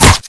hit-body.wav